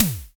Index of /90_sSampleCDs/Roland L-CD701/DRM_Analog Drums/SNR_Analog Snrs
SNR BRITES04.wav